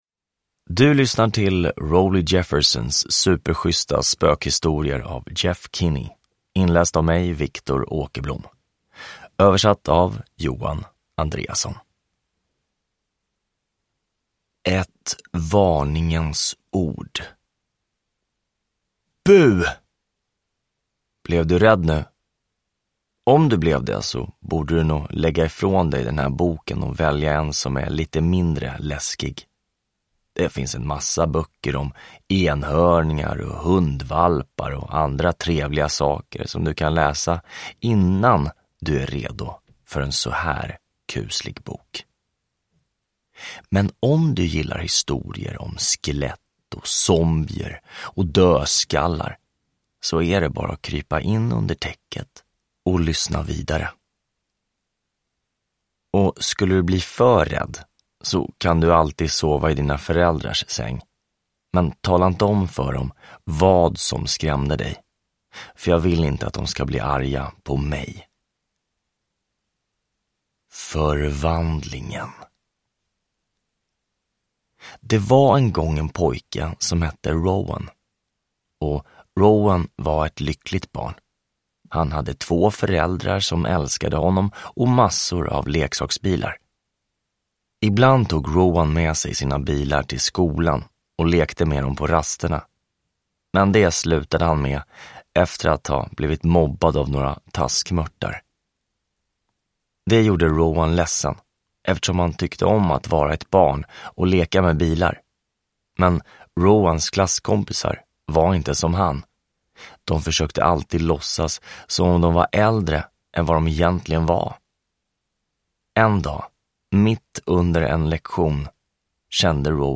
Rowley Jeffersons superschyssta spökhistorier – Ljudbok – Laddas ner